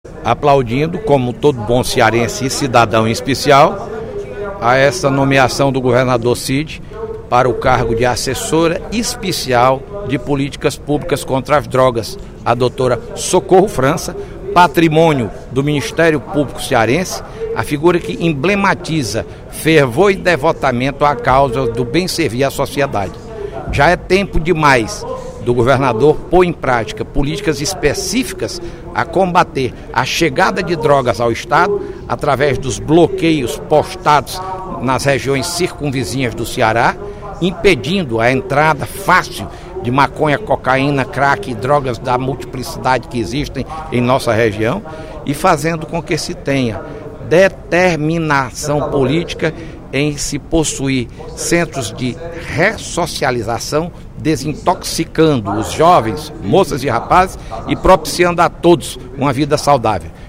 O deputado Fernando Hugo (PSDB) comentou, no primeiro expediente da sessão plenária da Assembleia Legislativa desta quinta-feira (27/12), a nomeação da ex-procuradora Socorro França para o cargo de Assessora Especial de Políticas Públicas de Combate às Drogas, prevista para as 14h de hoje, no Palácio da Abolição, sede do Executivo estadual.